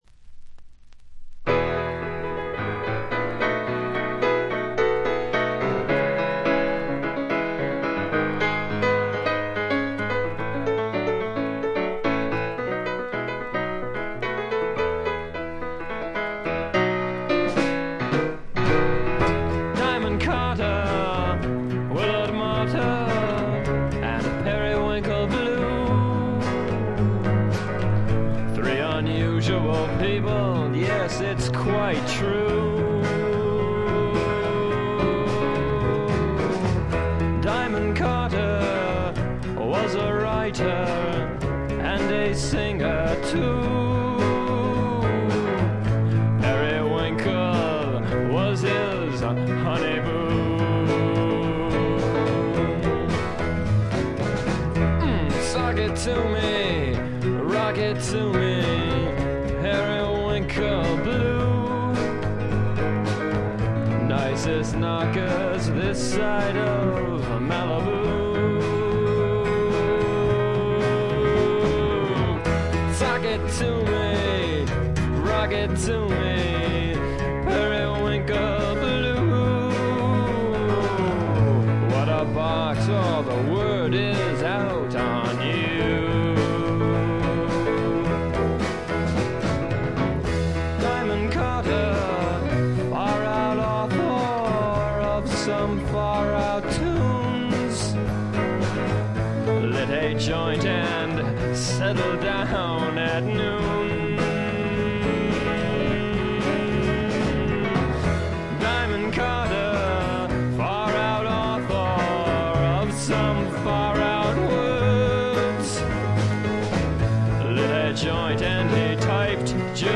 軽微なチリプチ。散発的なプツ音2回ほど。
試聴曲は現品からの取り込み音源です。